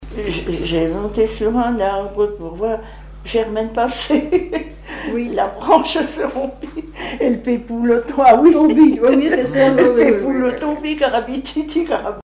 Commentaire sur la chanson Le petit cordonnier